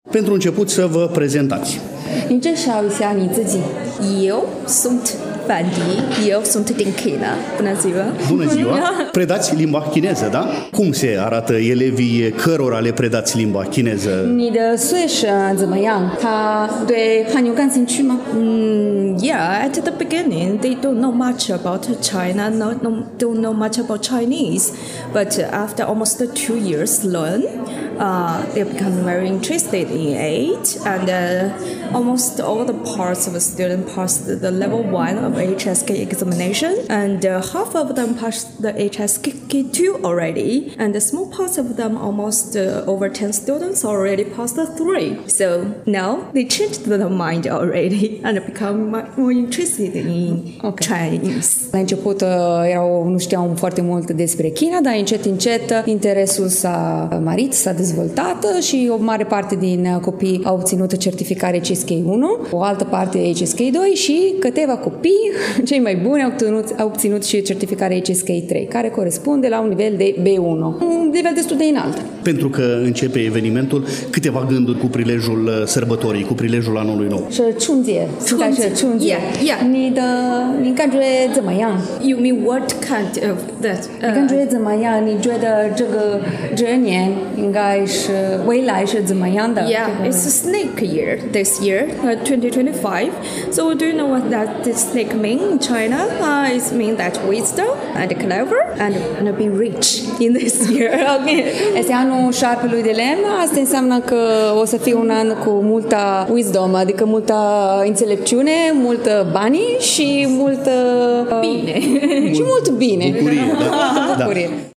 Dacă în două ediții trecute ale emisiunii noastre am vorbit despre Anul Nou Chinezesc, cu accent pe câteva activități culturale organizate cu acest prilej atât în incinta Fundației EuroEd din Iași, cât și în incinta Universității de Medicină și Farmacie „Gr. T. Popa” Iași, astăzi continuăm a difuza câteva înregistrări din timpul sărbătorii pe care am amintit-o.